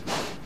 猪 呼吸3 96k Hz
声道立体声